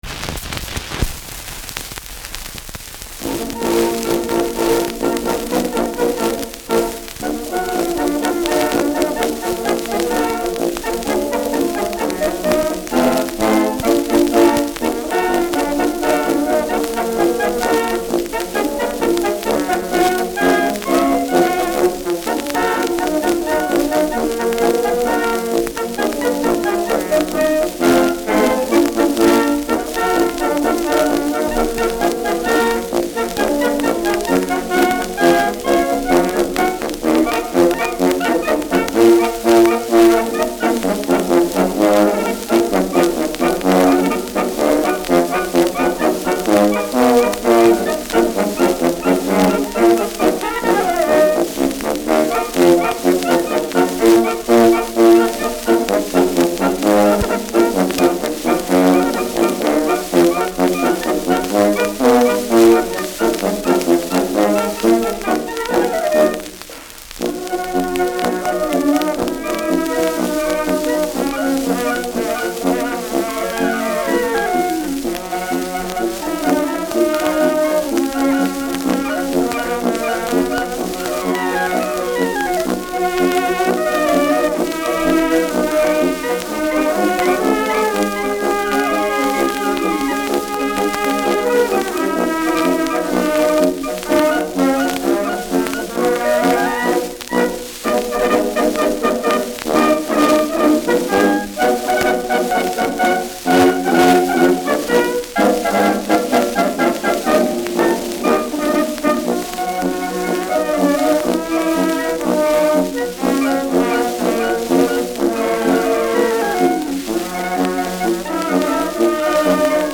Schellackplatte
Schützenkapelle* FVS-00006